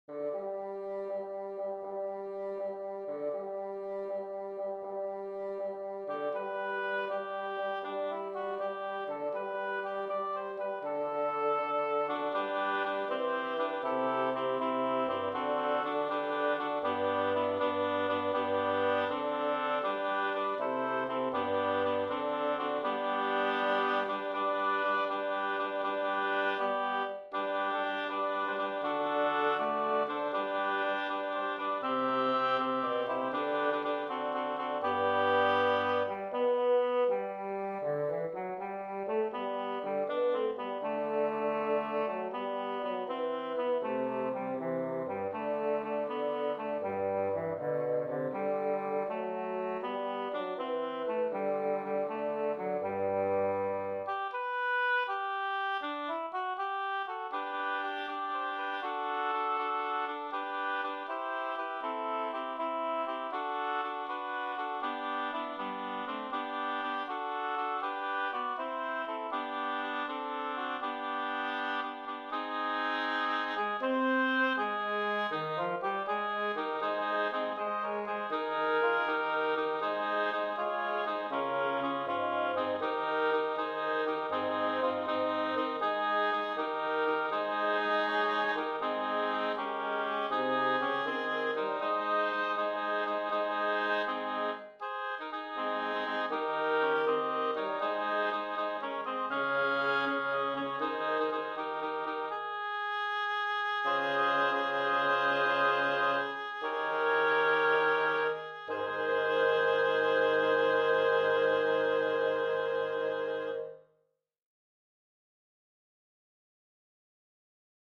Refreshingly clear choral arrangement.